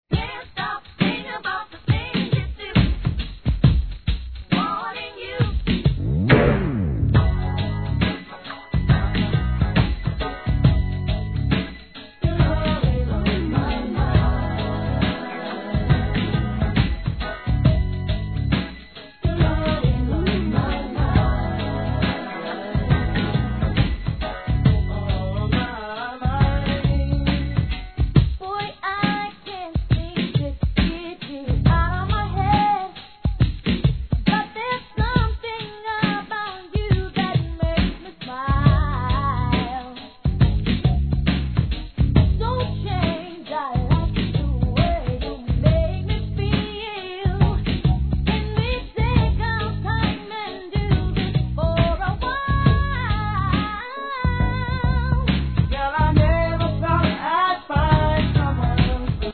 HIP HOP/R&B
1993年、清涼感溢れるヴォーカルワークが載るミディアム・ナンバー♪